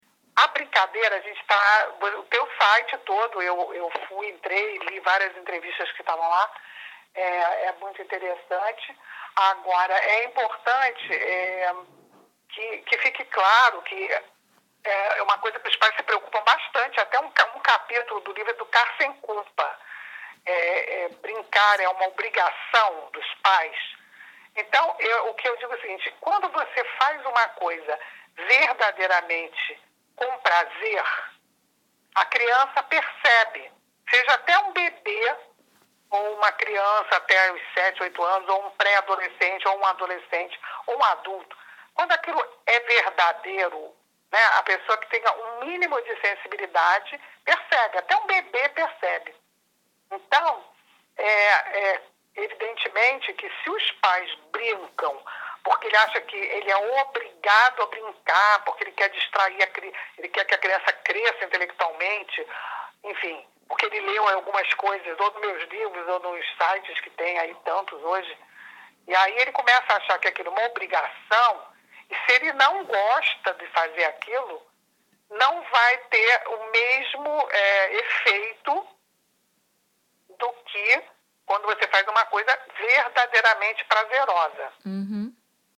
Entrevista especial com Tania Zagury- FOTO TANIA